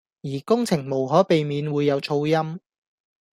Гонконгский 738